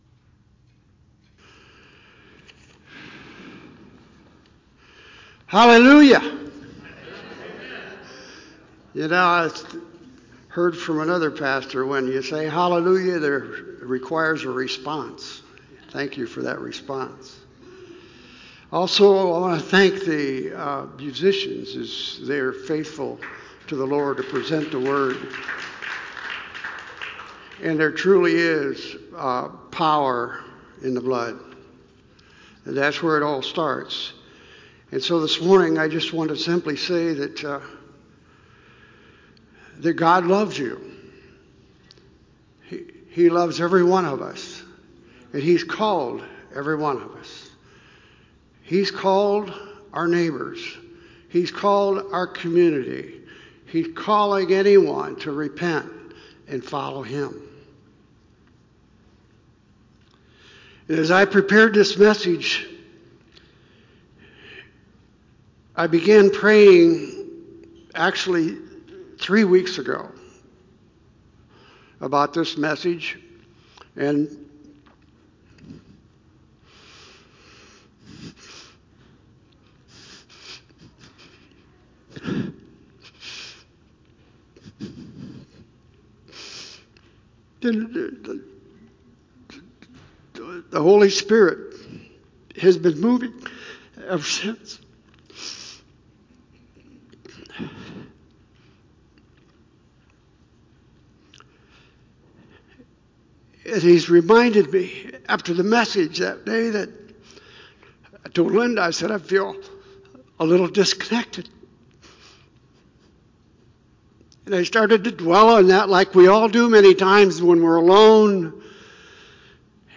Unfortunately due to technical difficulties this week, we only have the audio portion of the sermon.